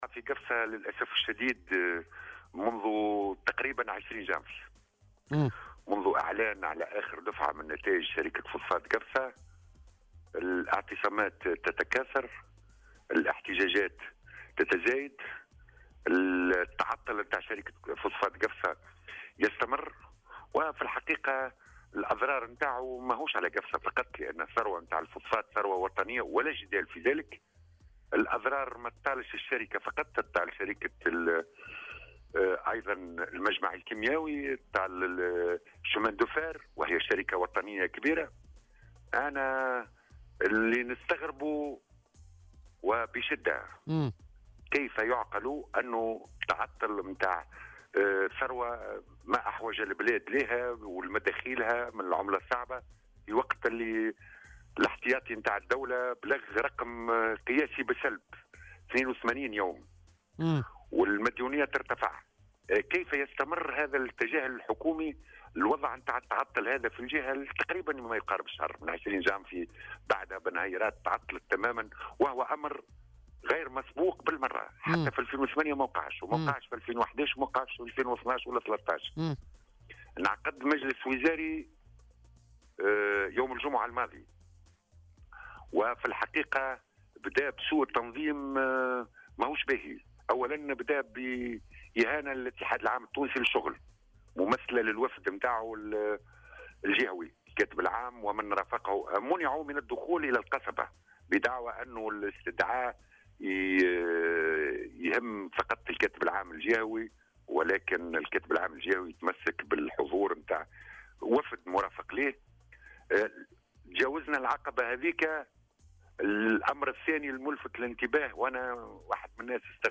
وأضاف في مداخلة له اليوم في برنامج "بوليتيكا" أن هذه التحركات كان لها تأثير سلبي على سير العمل داخل شركة فسفاط قفصة والمجمع الكيميائي و كذلك شركة السكك الحديدية، معتبرا أن مخرجات المجلس الوزاري الأخير لم تكن واضحة ودقيقة وفيها الكثير من الوعود والتسويف، وفق تعبيره.